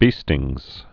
(bēstĭngz)